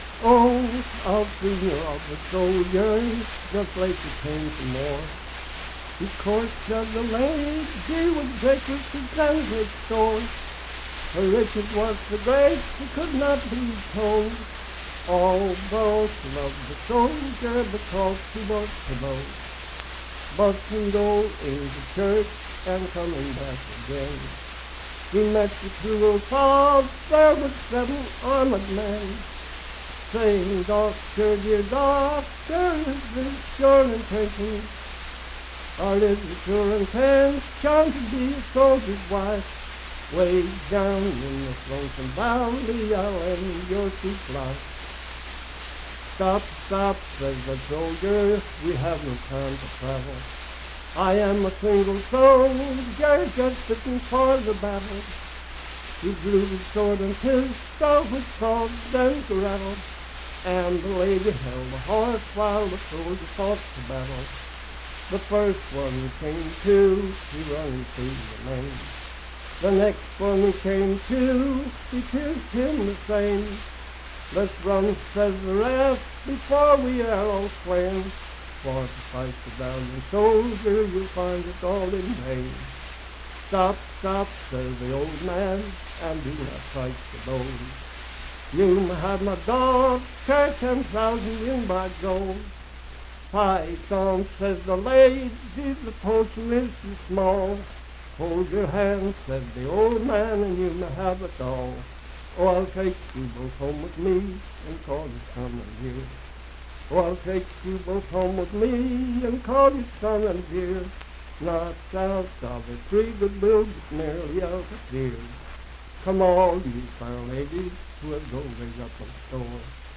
Unaccompanied vocal music
in Mount Storm, W.V.
Verse-refrain 8d(4).
Voice (sung)